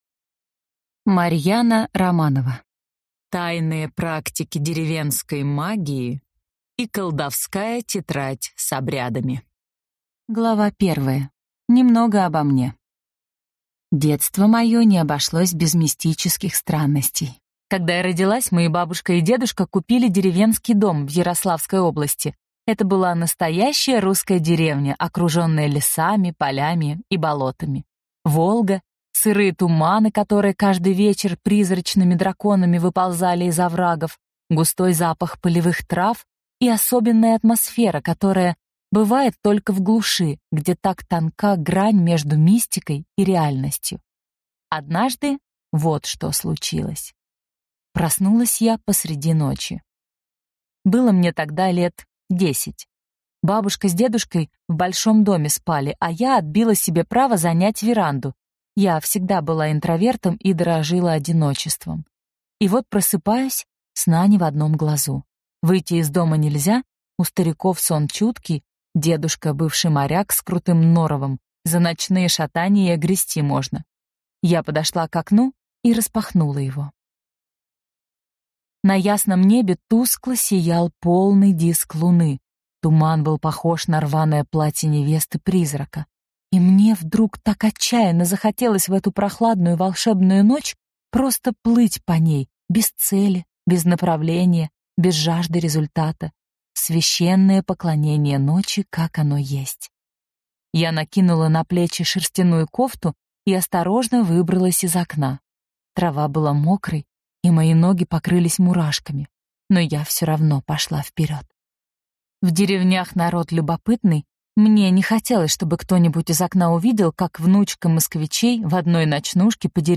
Аудиокнига Тайные практики деревенской магии + колдовская тетрадь с обрядами | Библиотека аудиокниг